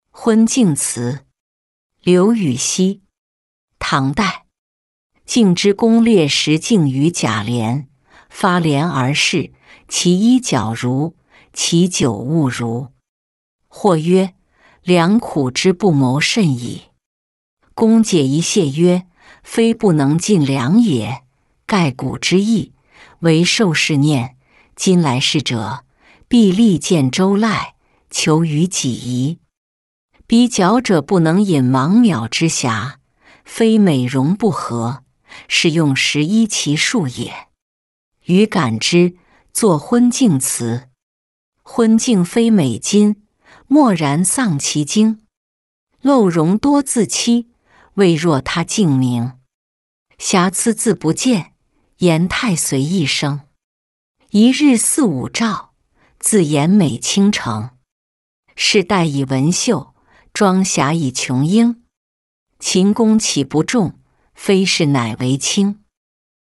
昏镜词-音频朗读